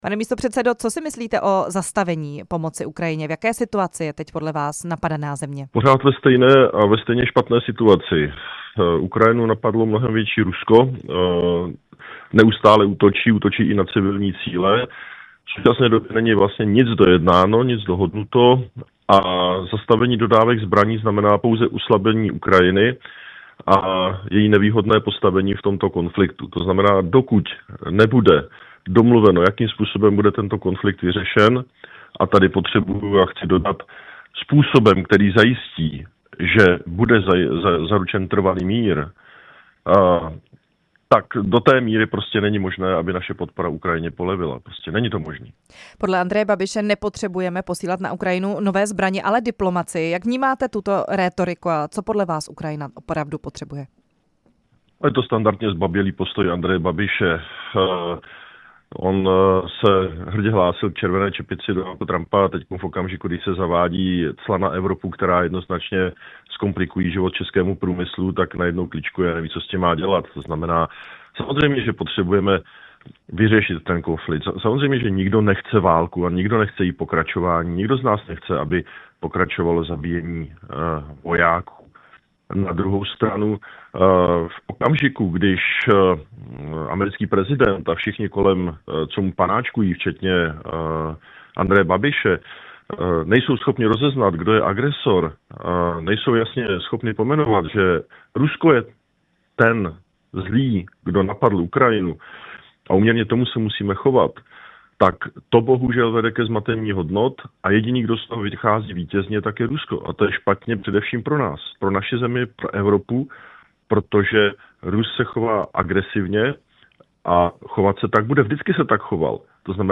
Hostem vysílání Radia Prostor byl místopředseda Poslanecké sněmovny Jan Bartošek z KDU-ČSL.
Rozhovor s místopředsedou Poslanecké sněmovny Janem Bartoškem (KDU-ČSL)